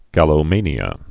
(gălō-mānē-ə, -mānyə)